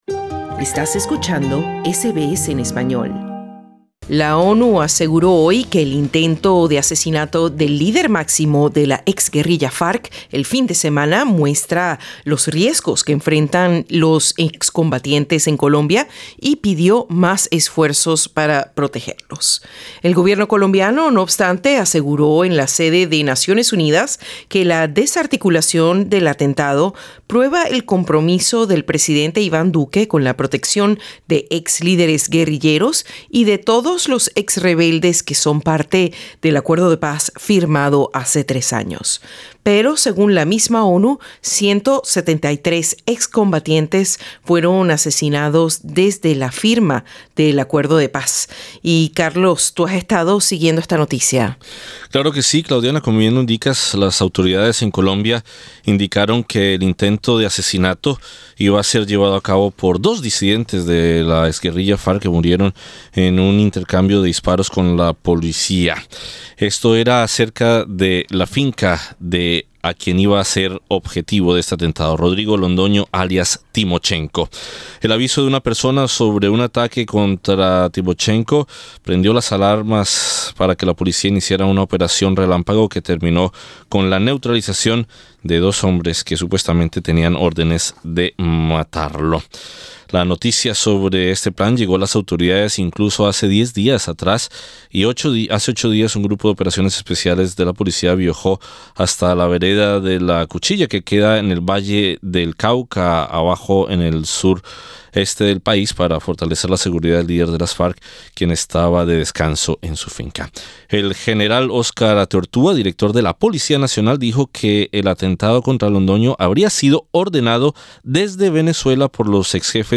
Escucha la entrevista con el senador del partido Polo Democrático de Colombia, Iván Cepeda.